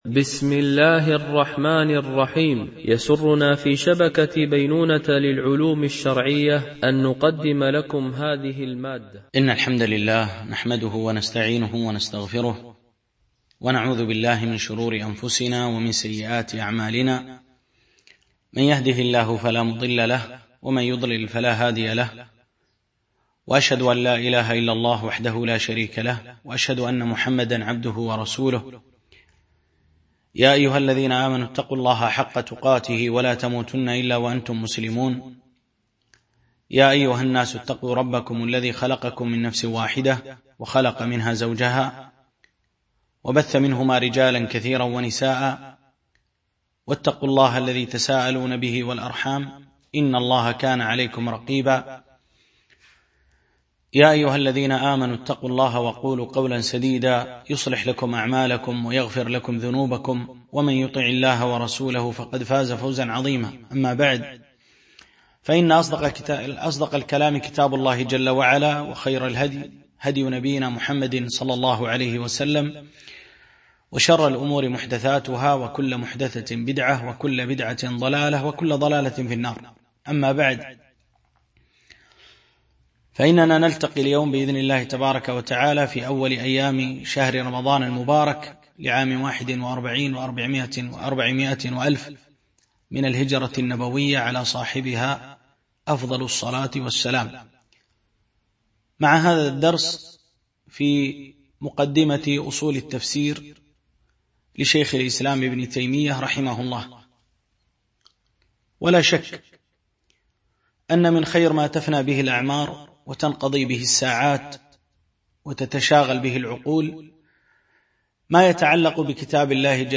شرح مقدمة في أصول التفسير ـ الدرس 1